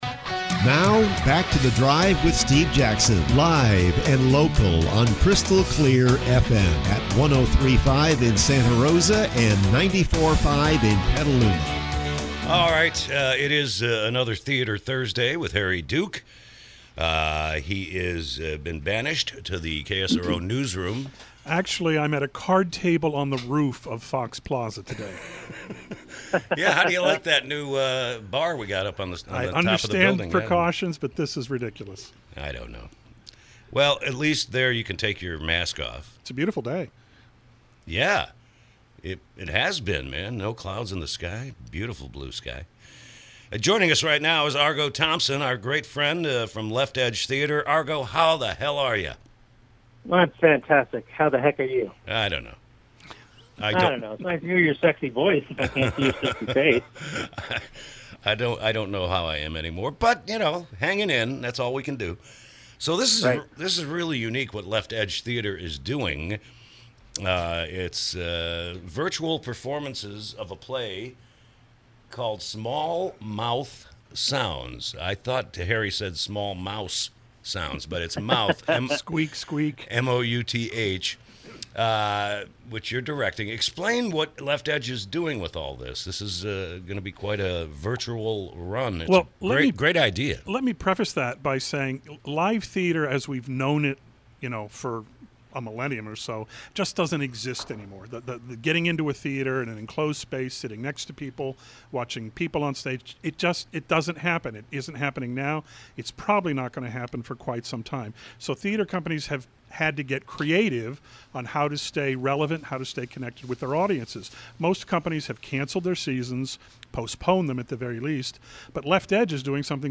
KSRO Interview – “Small Mouth Sounds”